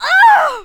ogg / general / combat / creatures / alien / she / die1.ogg
die1.ogg